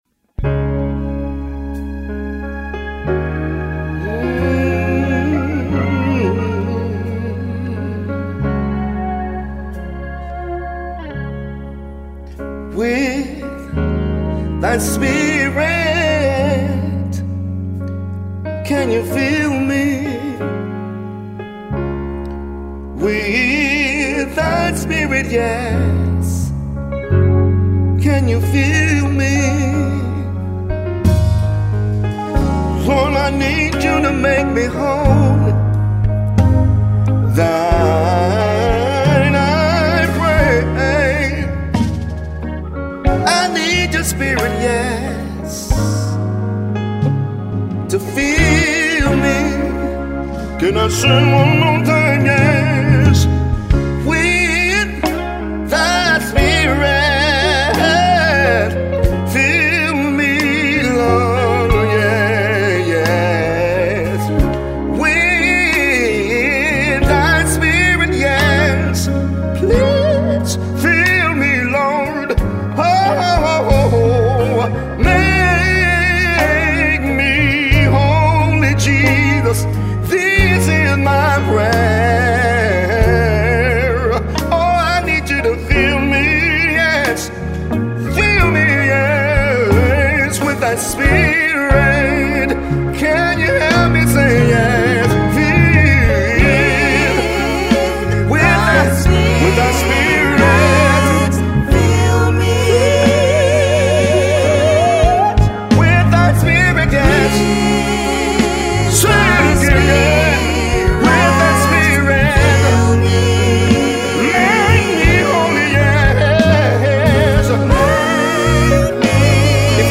Contemporary Christian Music Artist